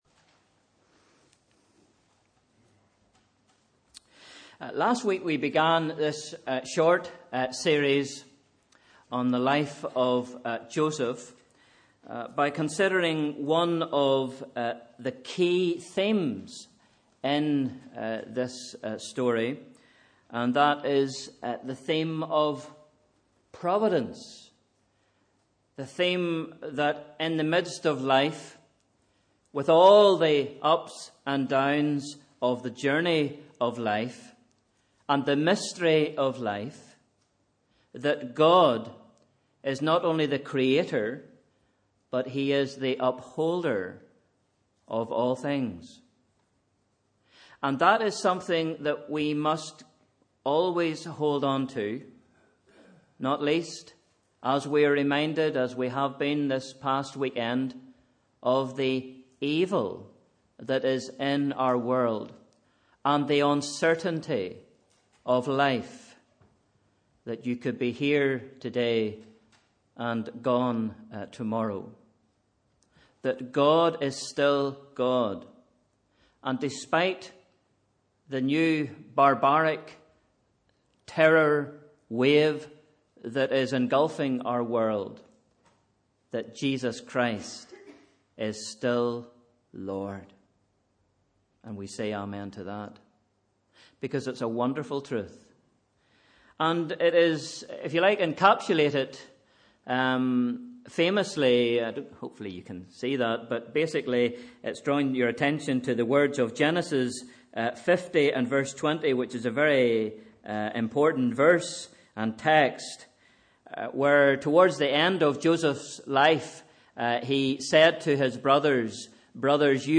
Sunday 15th November 2015 – Evening Service